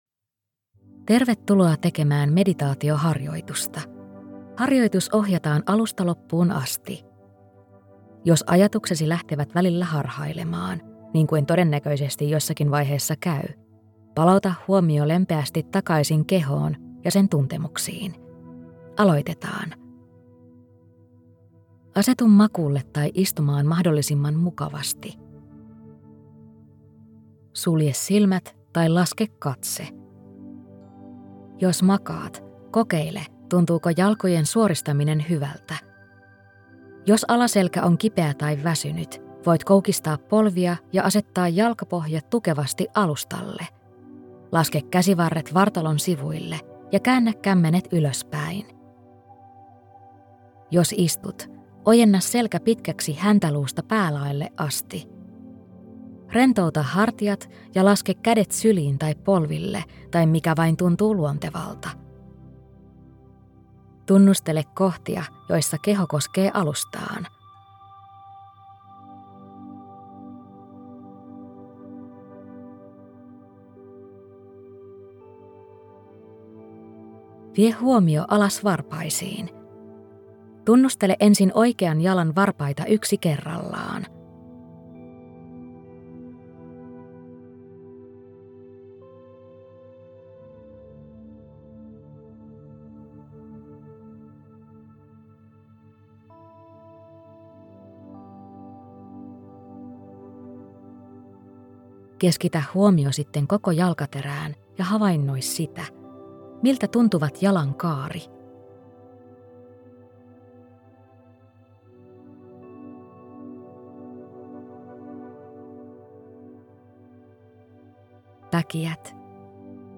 Kehoskannaus – kuunneltava meditaatioharjoitus